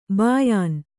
♪ bāyān